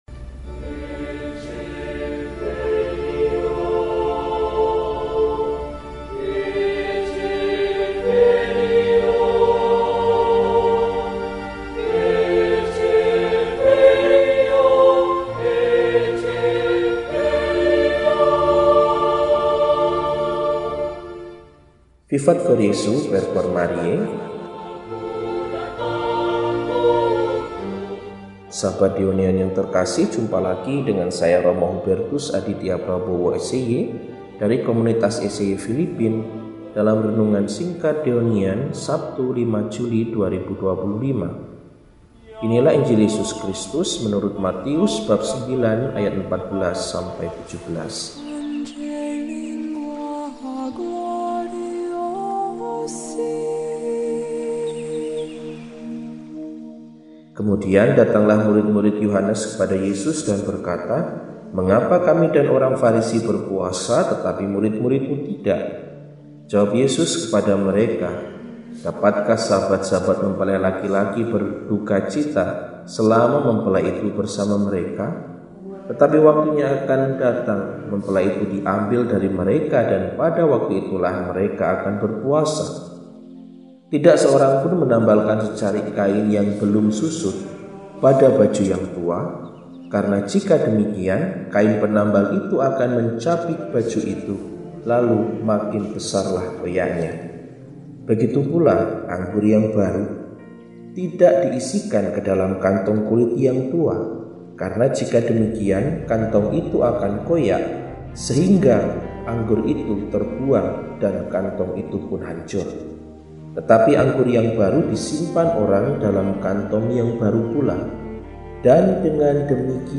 Sabtu, 05 Juli 2025 – Hari Biasa Pekan XIII – RESI (Renungan Singkat) DEHONIAN